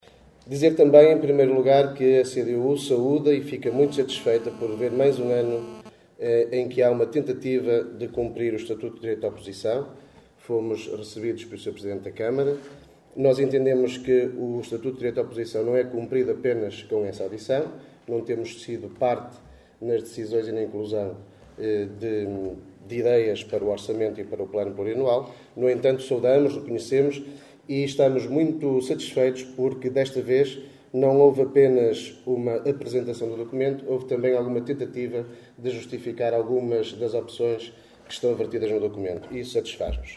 A posição da bancada social democrata ficou expressa através da declaração de voto apresentada pelo líder da bancada Rui Taxa.
Extratos da última reunião extraordinária da Assembleia Municipal de Caminha que decorreu na passada sexta-feira Teatro Valadares.